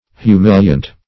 Search Result for " humiliant" : The Collaborative International Dictionary of English v.0.48: Humiliant \Hu*mil"i*ant\, a. [L. humilians, p. pr. of humiliare.]